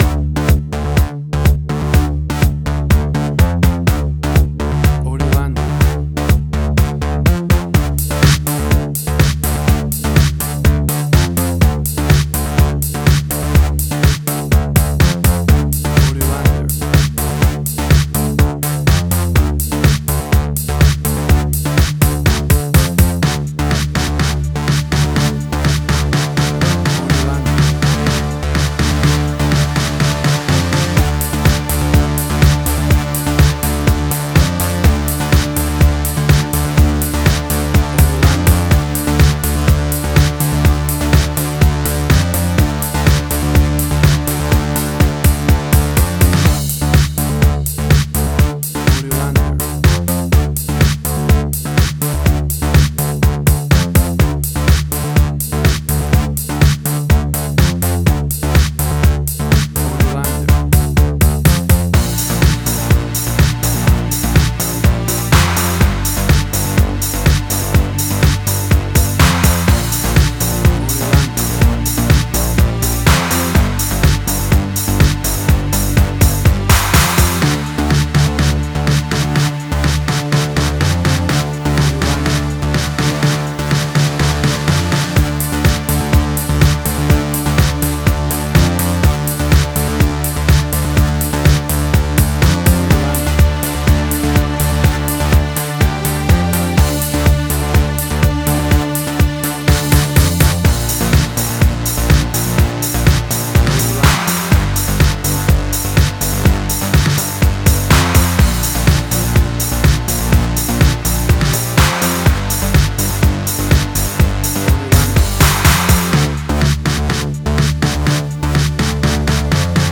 Future Retro Wave Similar Stranger Things New Wave.
WAV Sample Rate: 16-Bit stereo, 44.1 kHz
Tempo (BPM): 124